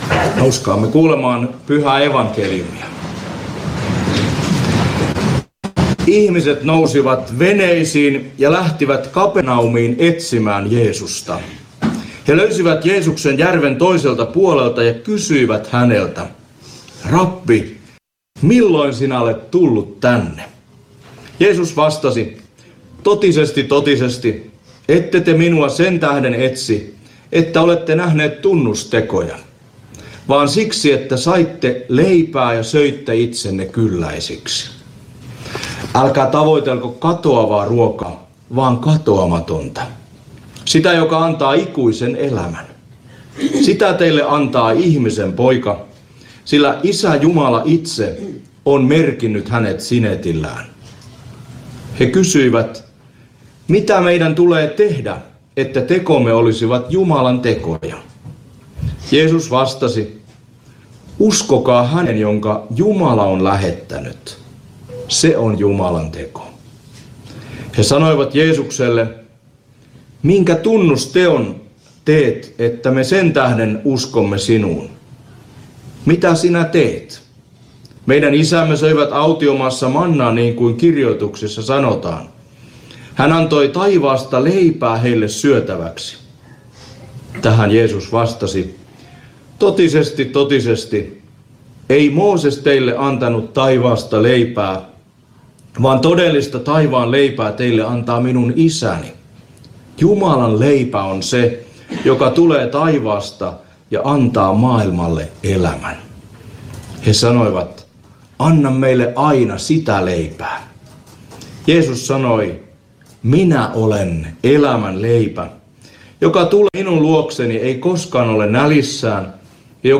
saarna